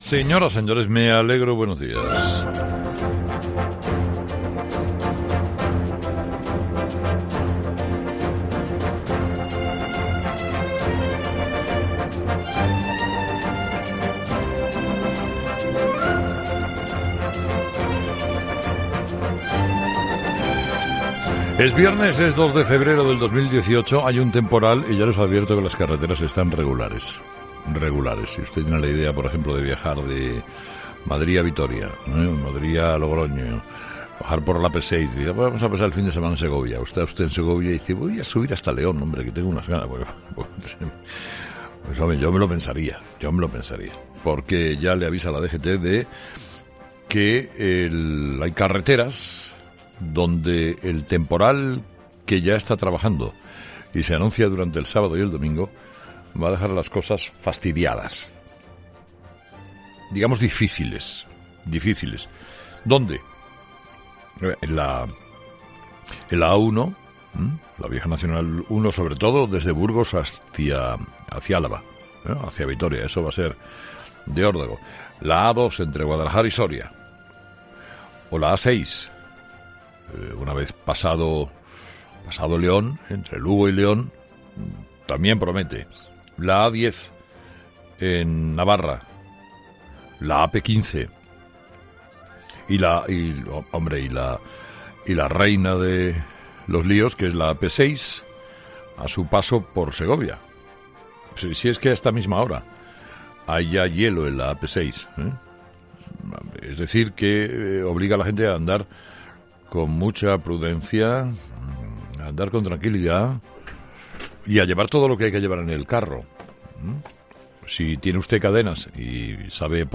Monólogo 8h, viernes 2 de febrero de 2018